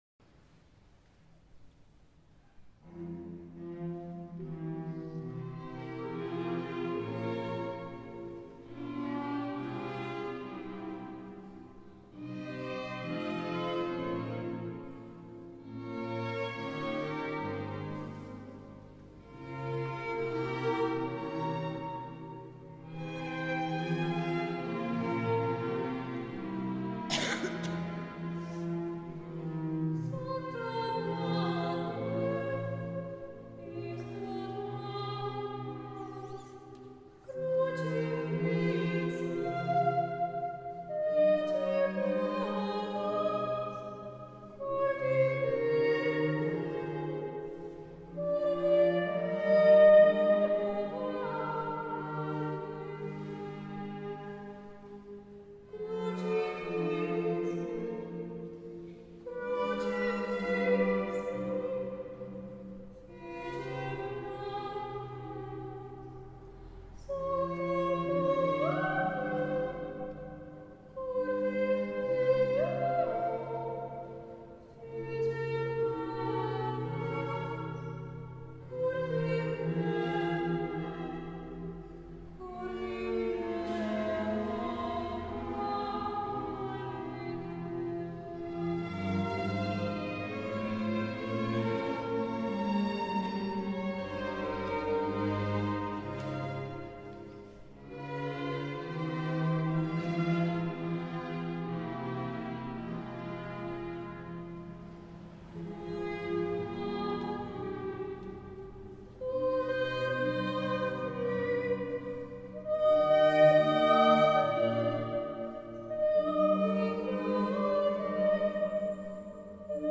Soloist Music